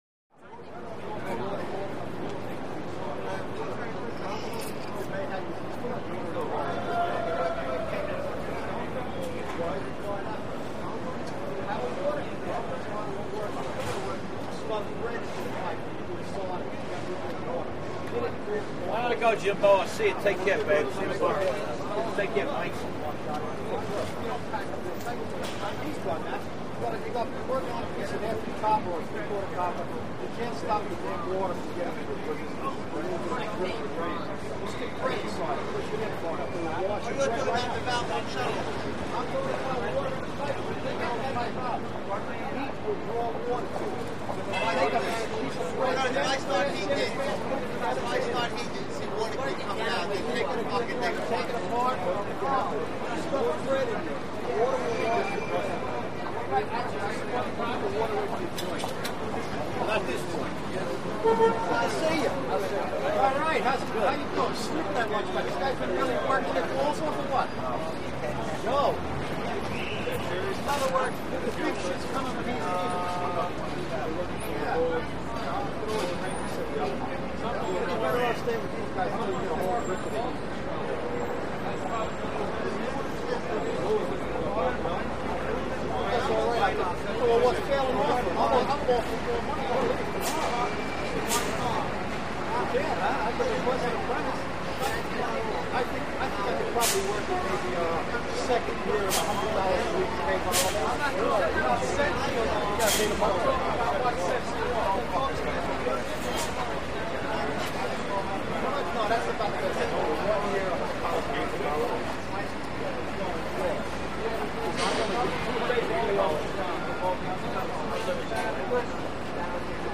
Male Group Walla With Street Ambience; Blue Collar Workers, Close To Medium Perspective. Background Traffic Ambience; Sparse Honks, Bus Air Brake Hisses, Movement.